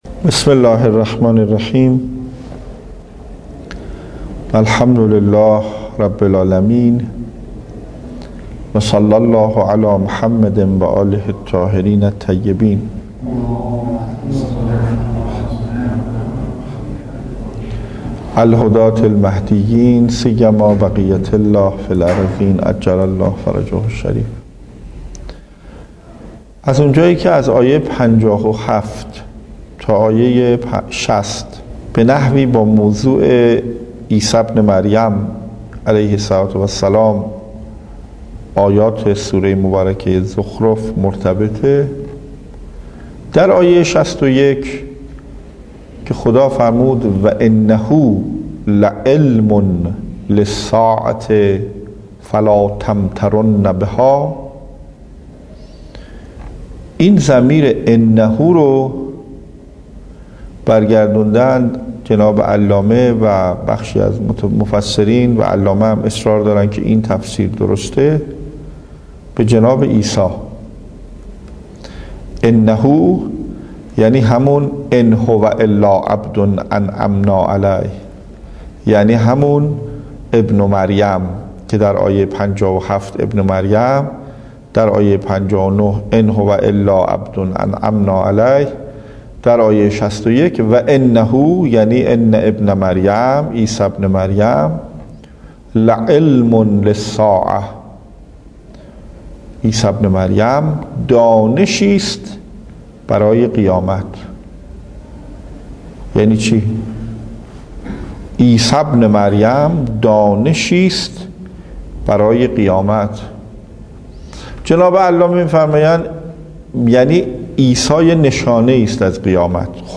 تفسیر قرآن